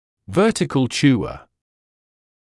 [‘vɜːtɪkl ‘ʧuːə][‘вёːтикл ‘чуːэ]человек с вертикальным типом жевания